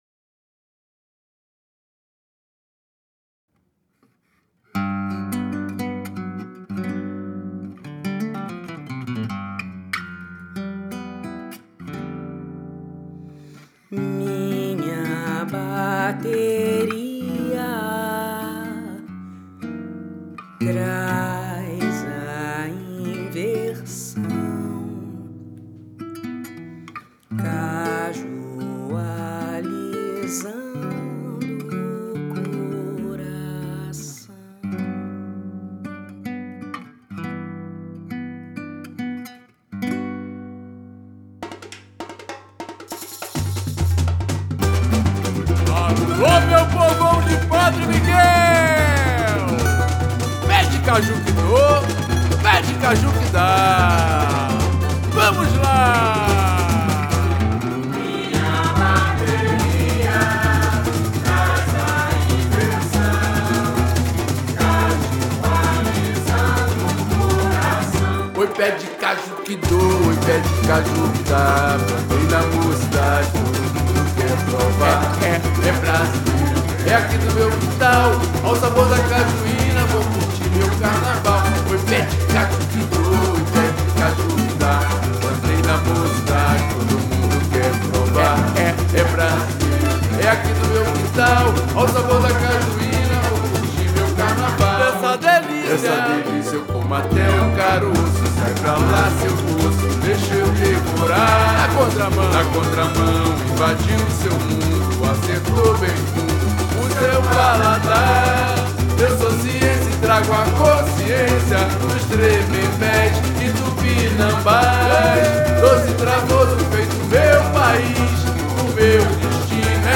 samba enredo
Conheça o samba: